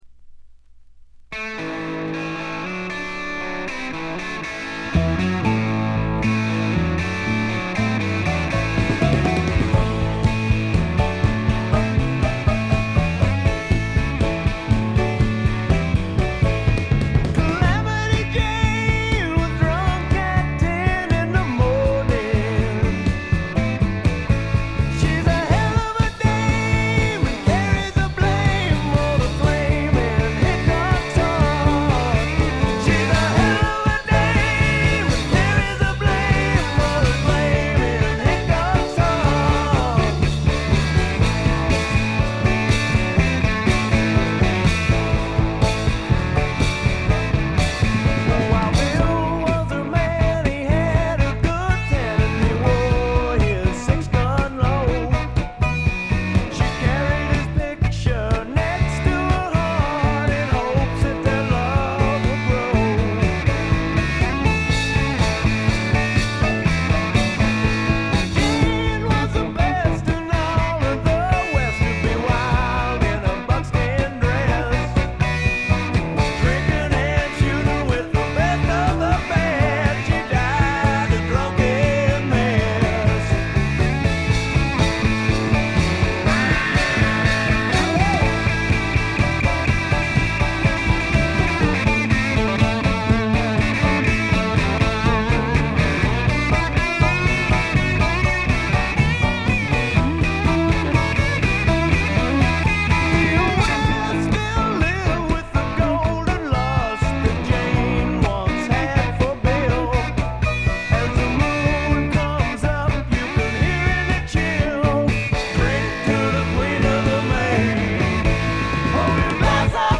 ごくわずかなノイズ感のみ。
試聴曲は現品からの取り込み音源です。
Drums, Vocals
Guitar, Vocals
Bass, Vocals
Keyboards, Vocals